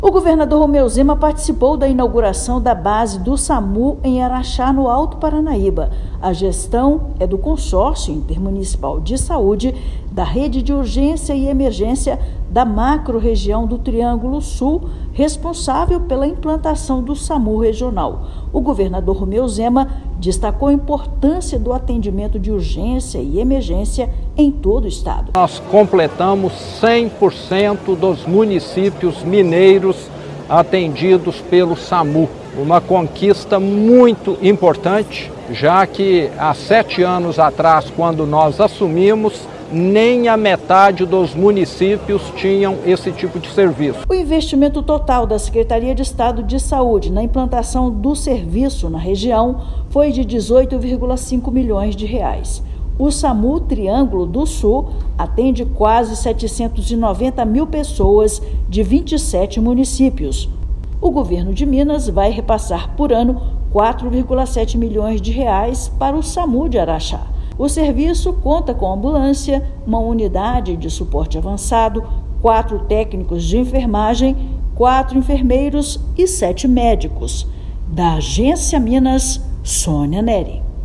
[RÁDIO] Governo de Minas inaugura base do Samu em Araxá, no Alto Paranaíba
Nova estrutura fortalece o acesso a serviços de urgência no interior do estado. Ouça matéria de rádio.